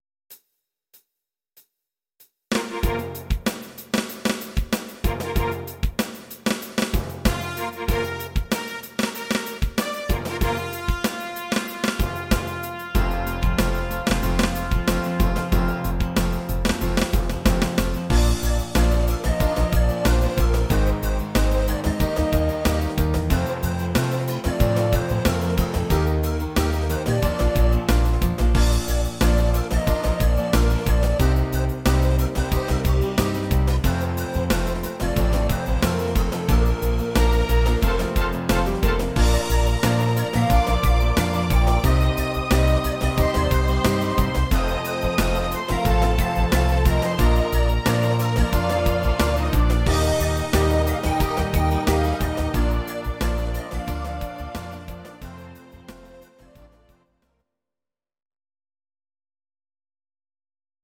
Audio Recordings based on Midi-files
Pop, Musical/Film/TV, 1960s